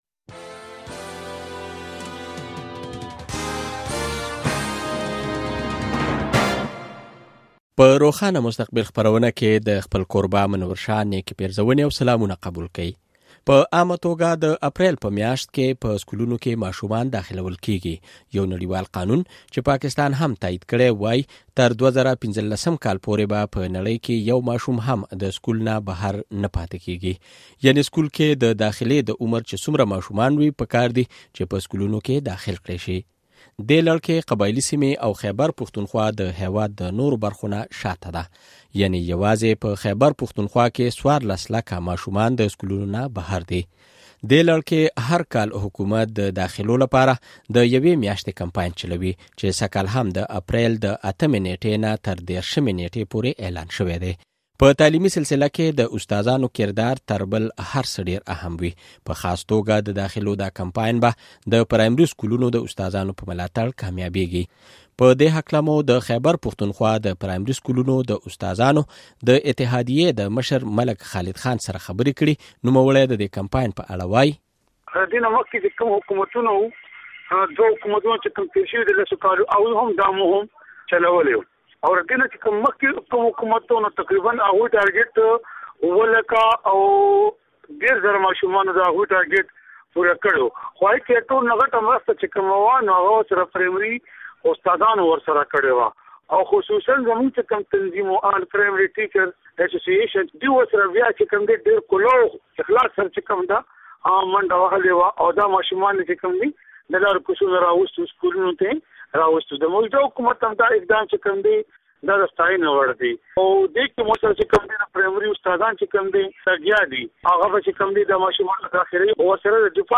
د خېبرپښتونخوا حکومت په صوبه کې د تعلیم عامولو لپاره داخله مهم شورو کړی چې یوه میاشت به دوام لري، د ننني روښان مستقبل خپرونه کې د دې کمپاین په باب، د استاذانو او چارواکو سره خبرې اورئ.